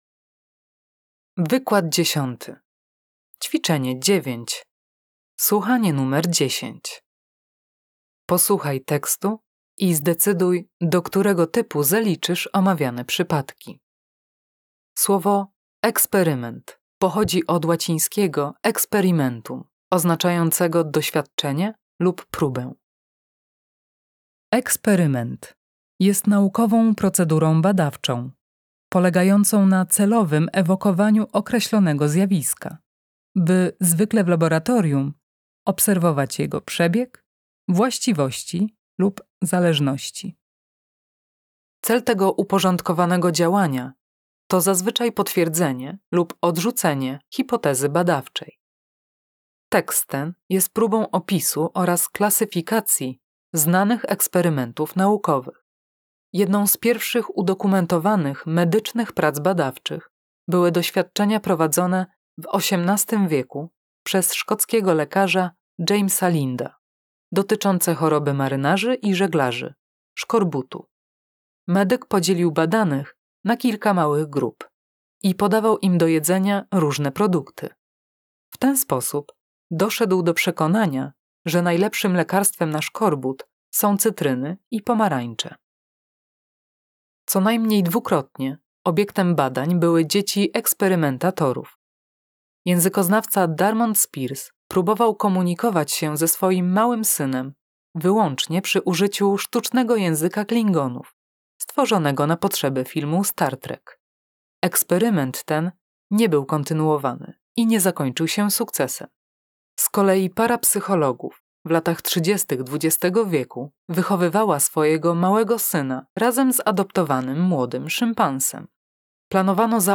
Wykład 10 - słuchanie nr 10